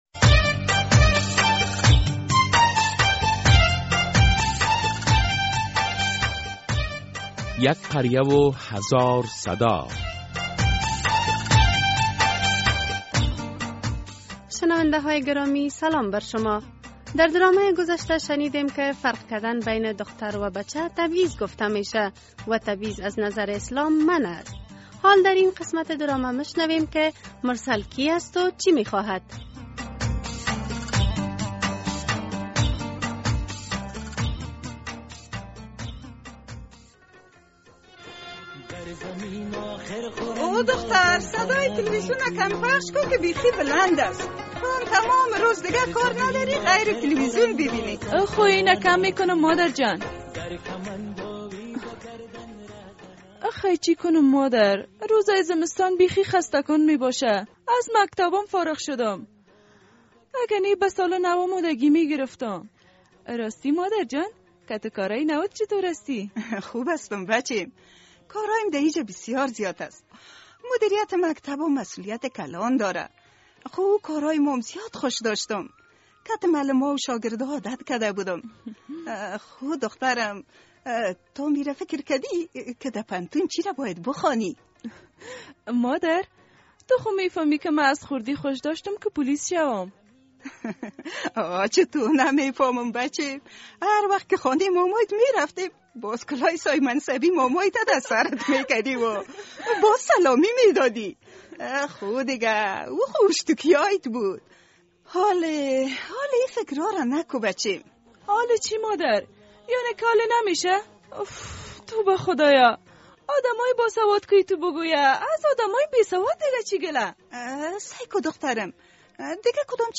در این درامه که موضوعات مختلف مدنی، دینی، اخلاقی، اجتماعی و حقوقی بیان می‌گردد هر هفته به روزهای دوشنبه ساعت ۳:۳۰ عصر از رادیو آزادی نشر می‌گردد...